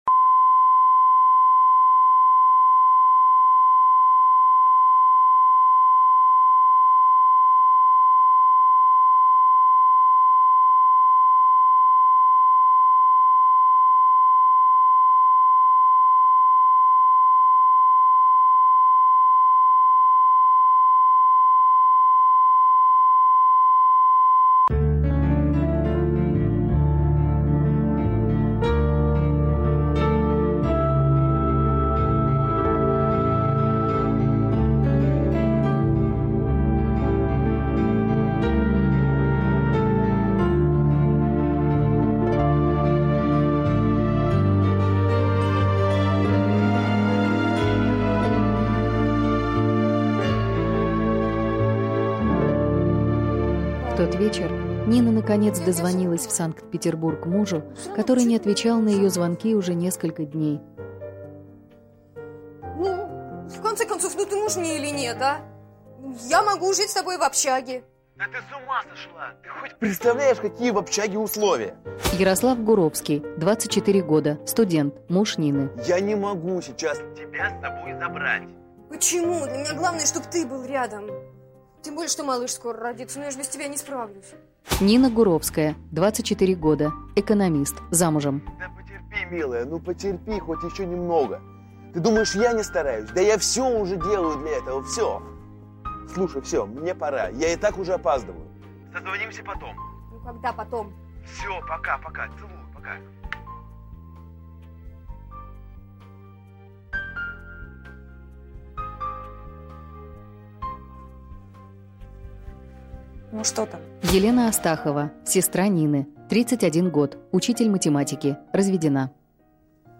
Аудиокнига Ничего важнее нет | Библиотека аудиокниг